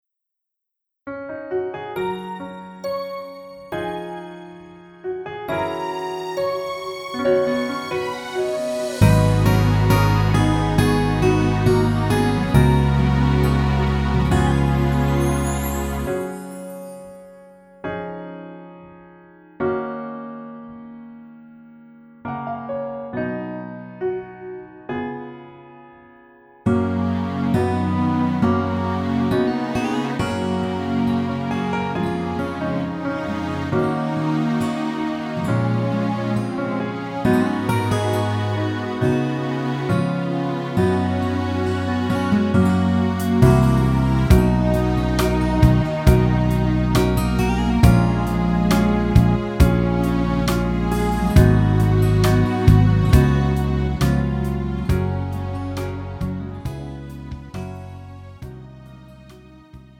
음정 원키
장르 가요 구분 Pro MR